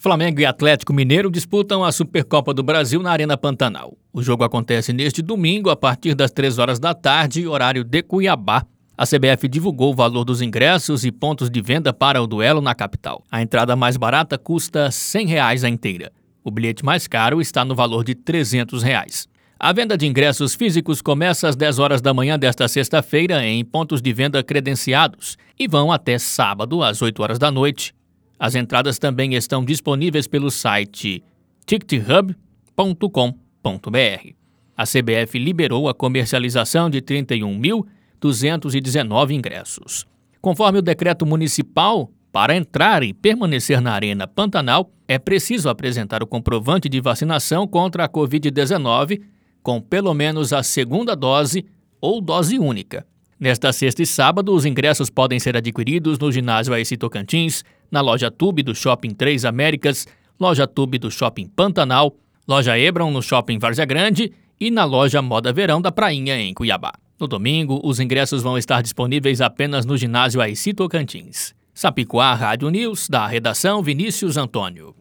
Boletins de MT 18 fev, 2022